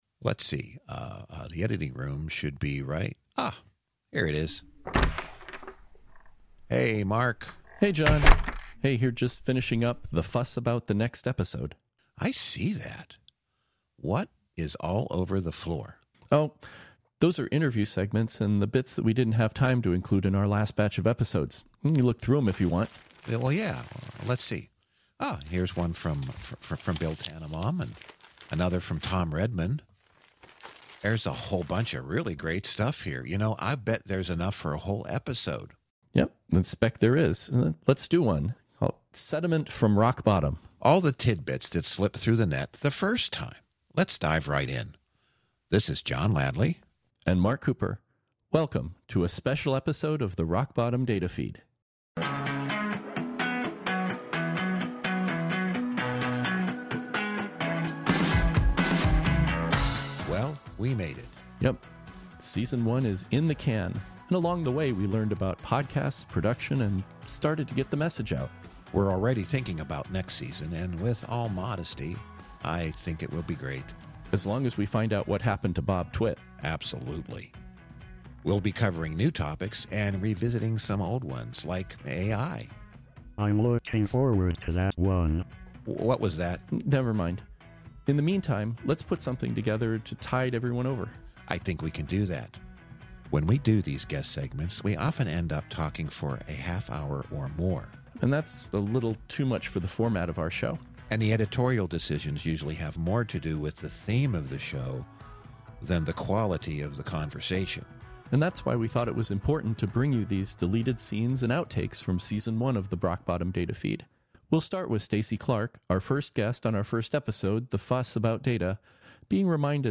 Sometimes when recording interviews we ended up with more material than we could use.
Never ones to waste anything, we have accumulated those interesting bits into an episode all their own. Perhaps hearing some of our past guests again will inspire you to go back and listen to their full episodes!